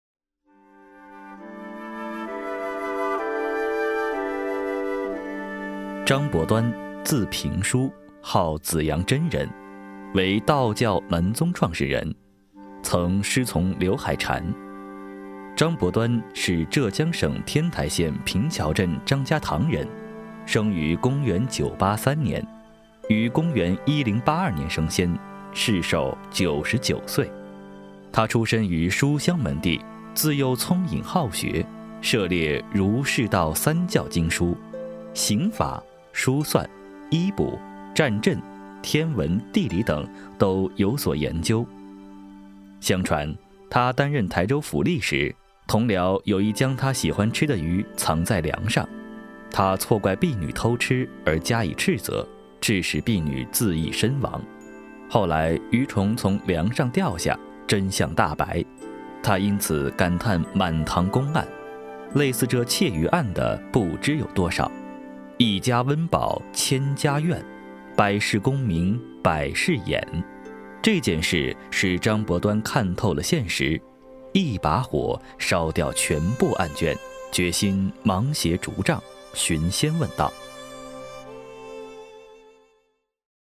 男52 纪录片  人物.mp3